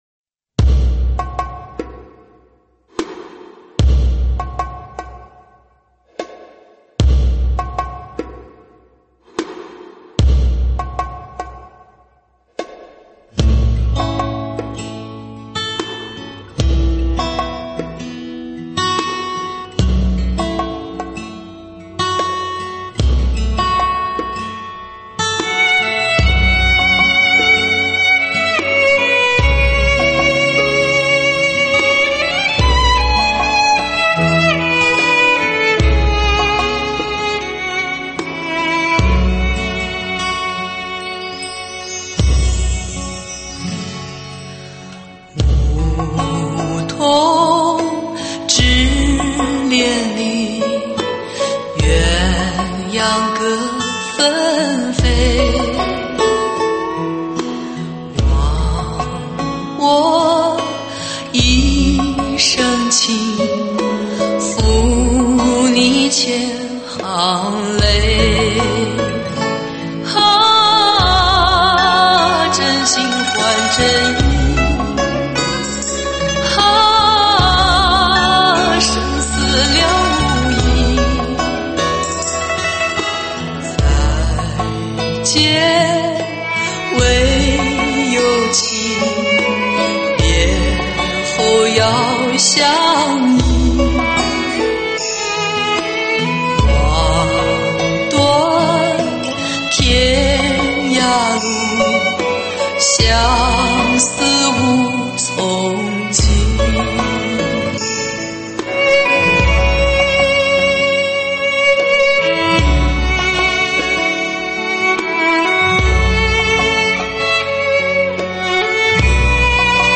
舞曲类别：周榜单